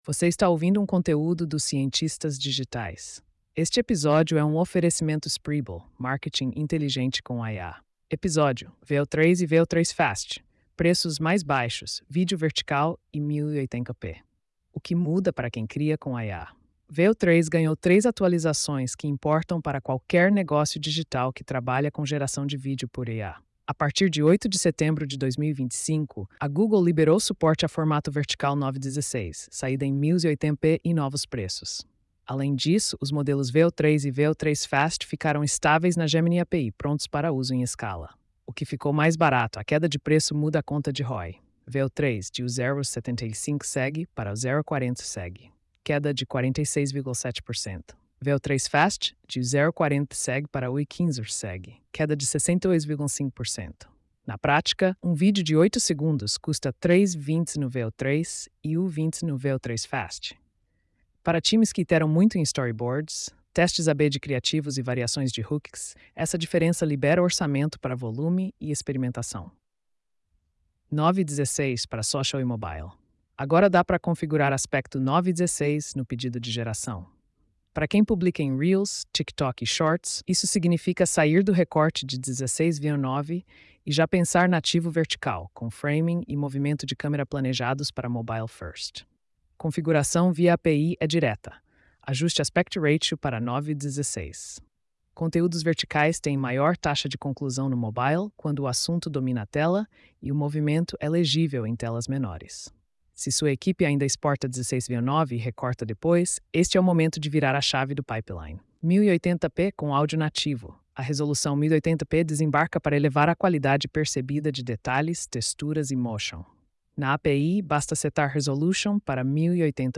post-4362-tts.mp3